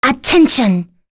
Rodina-Vox_Skill2_b.wav